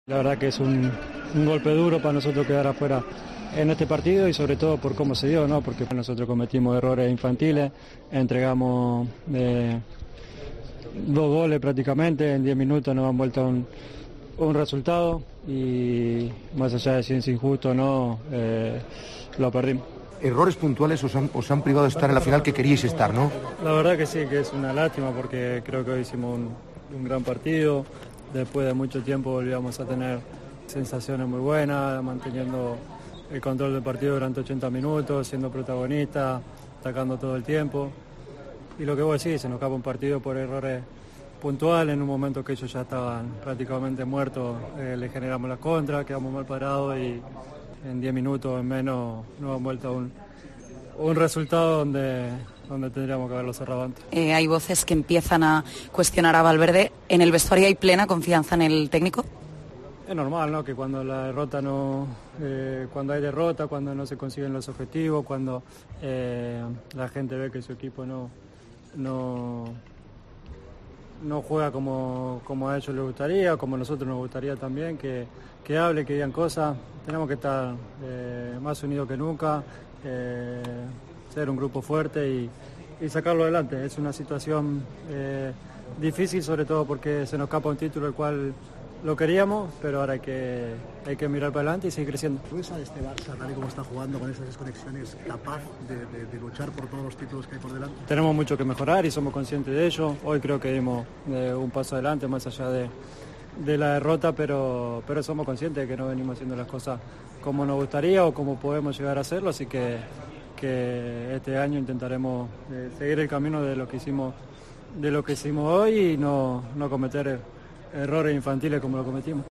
El capitán azulgrana habló de la dura derrota ante el Atlético que deja al equipo fuera de la final.